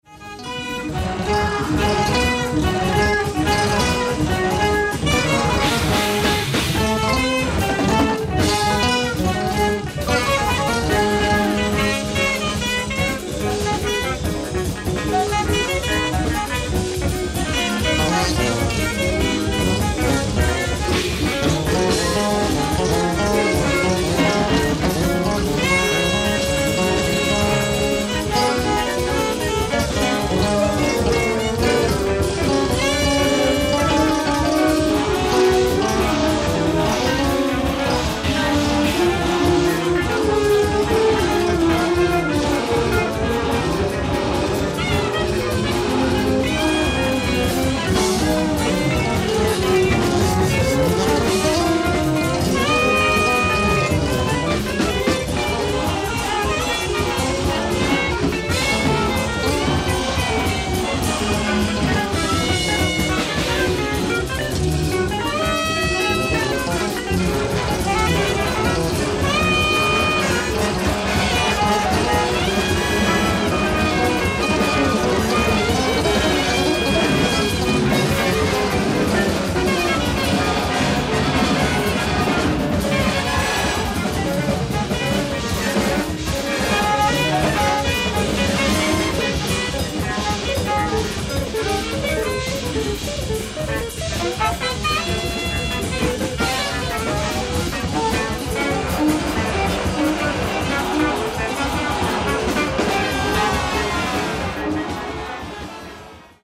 ライブ・アット・ベローナ・ジャズ、ベローナ、イタリア
※試聴用に実際より音質を落としています。